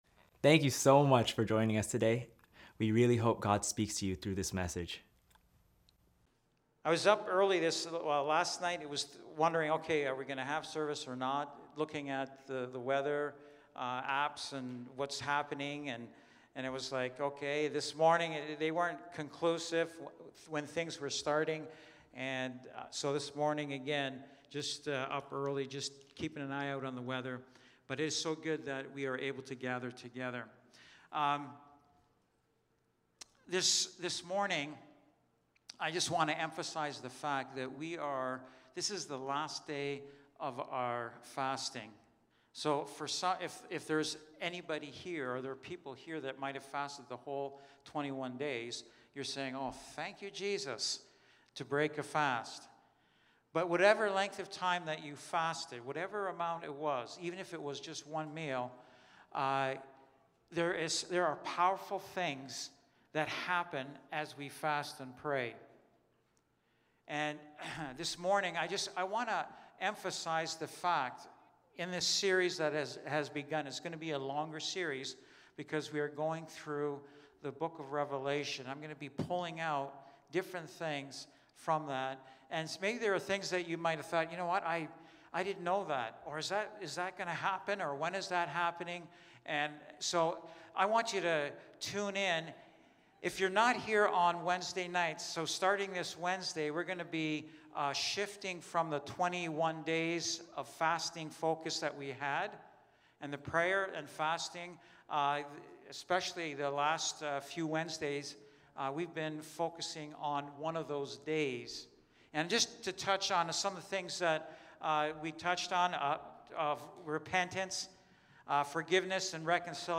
Lighthouse Niagara Sermons